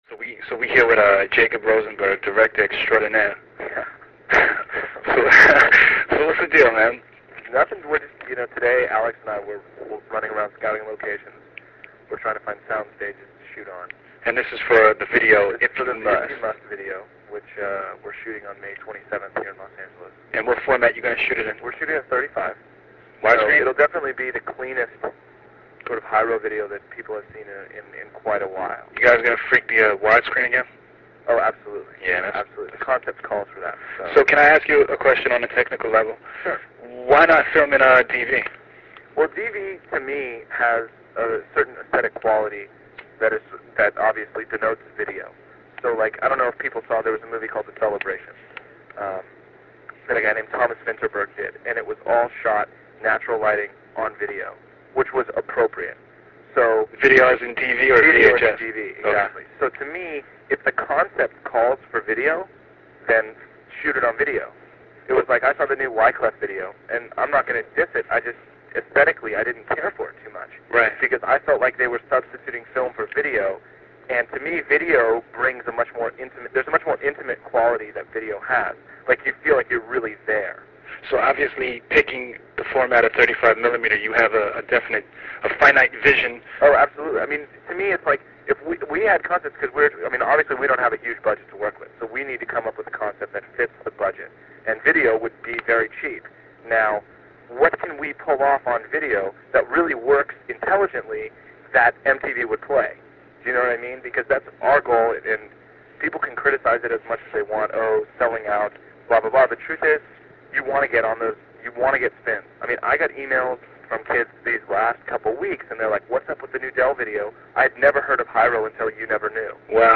If You Must - An Interview